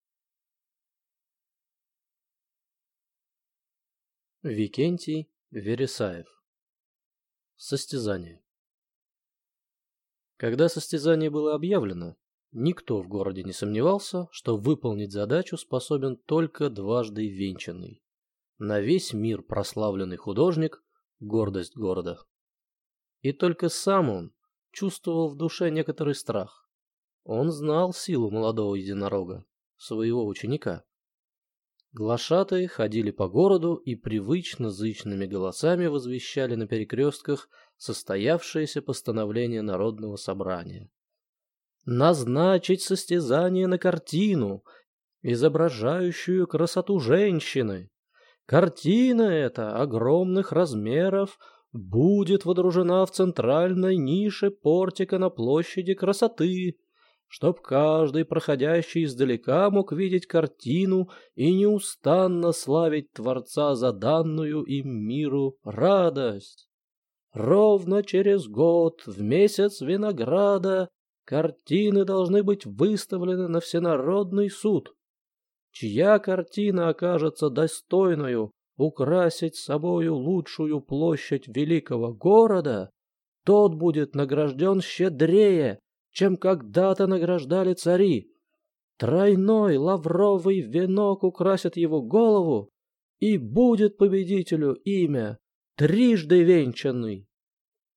Aудиокнига Состязание